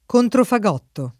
vai all'elenco alfabetico delle voci ingrandisci il carattere 100% rimpicciolisci il carattere stampa invia tramite posta elettronica codividi su Facebook controfagotto [ kontrofa g0 tto ] (meno bene contrafagotto ) s. m. (mus.)